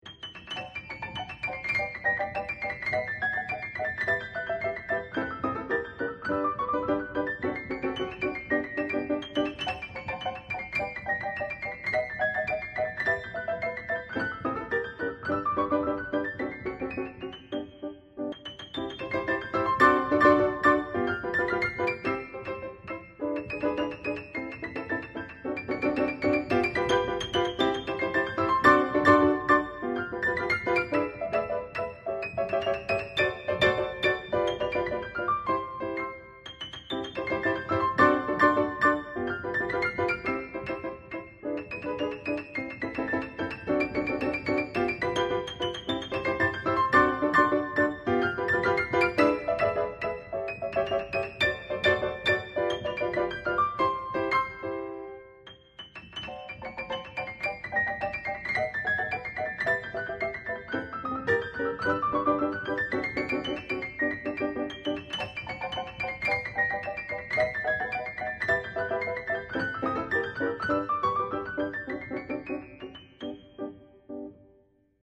巴西作曲家Nazareth / 鋼琴作品